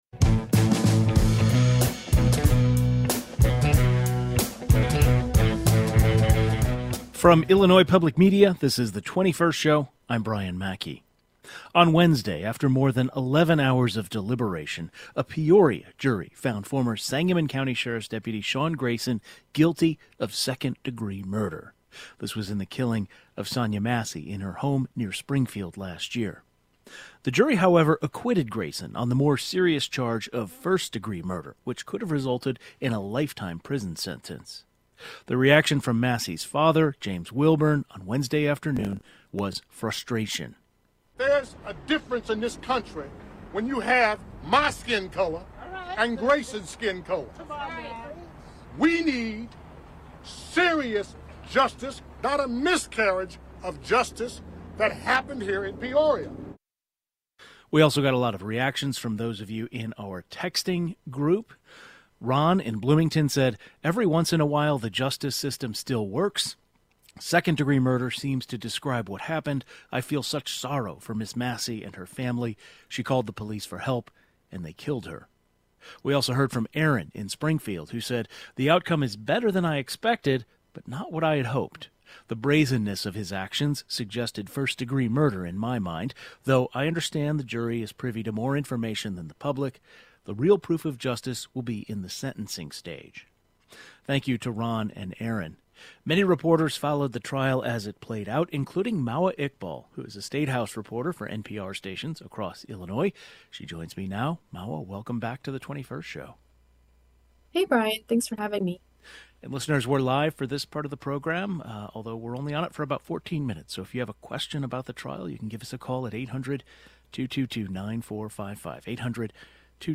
We'll talk with a reporter who covered the trial.